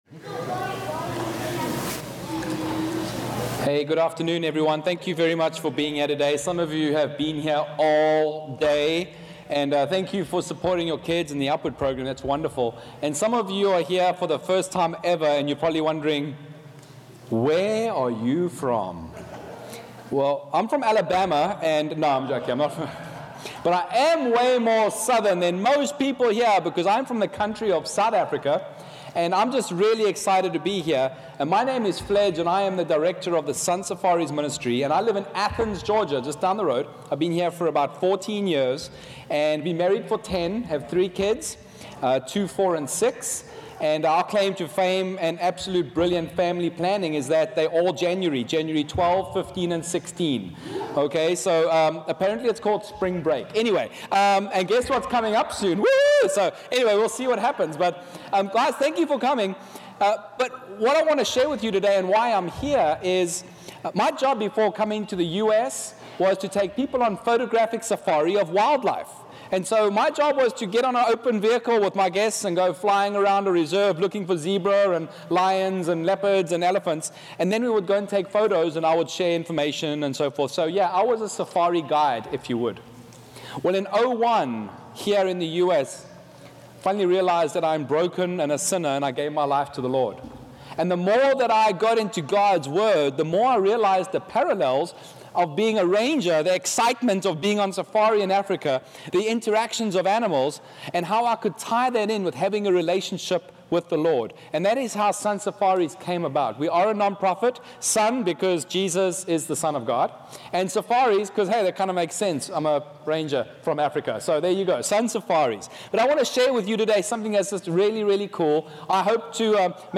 "Recorded Live"
Upward Basketball halftime devotional